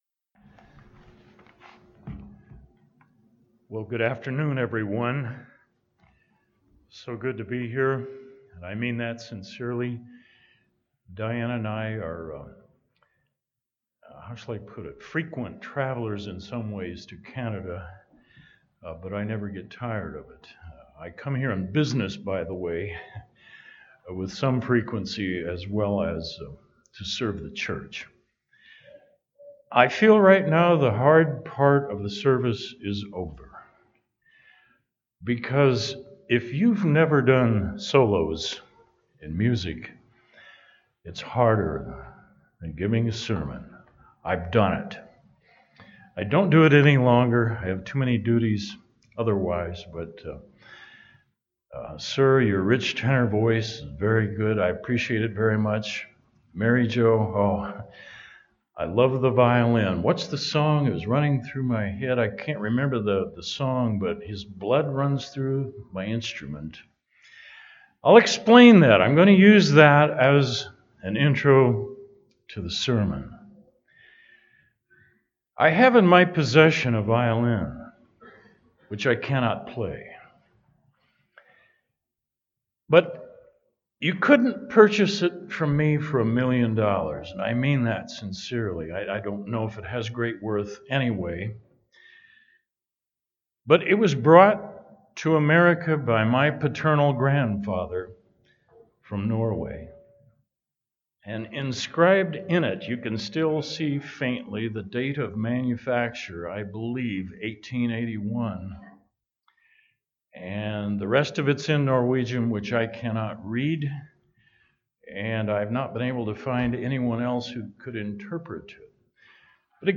This sermon was given at the Drumheller, Alberta 2018 Feast site.